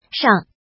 怎么读
shang
shang5.mp3